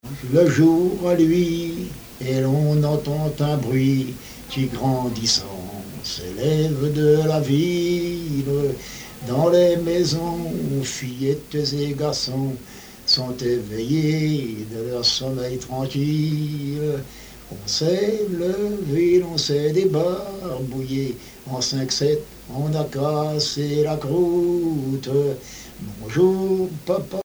Genre strophique
Chansons et commentaires